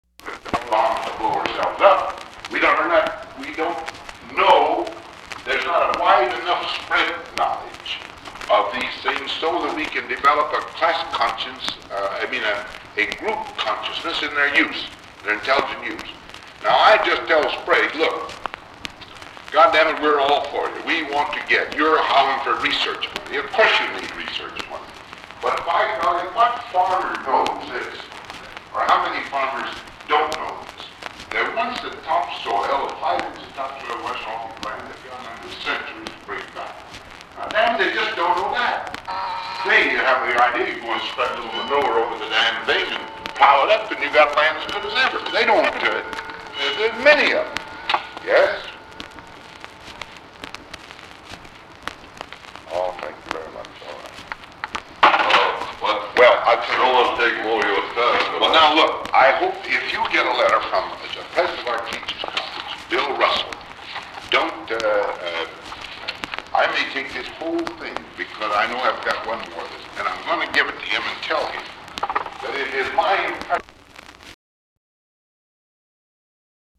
Recording begins with Eisenhower discussing agriculture with an unidentified man. There is an interruption and the man leaves .
Secret White House Tapes